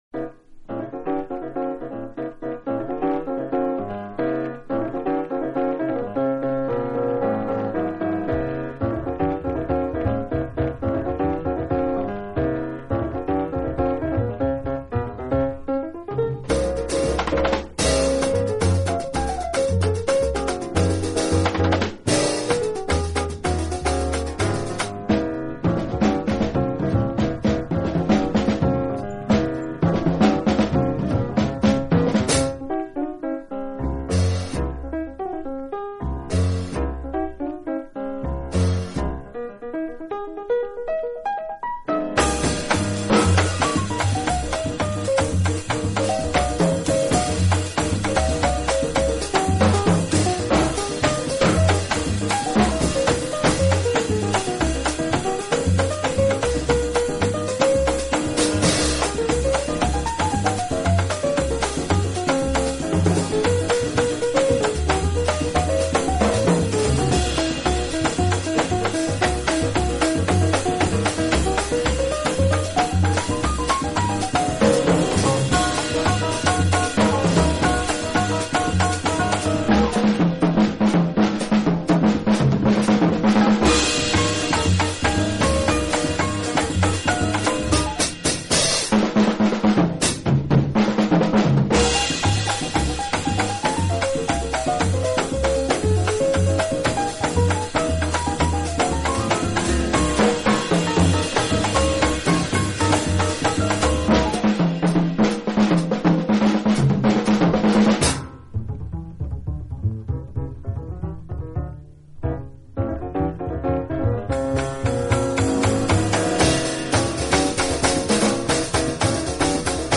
【巴西爵士】
巴西Bossa Nova音乐汇编，收集的全部都是以温柔娴雅著称的最难得的异国情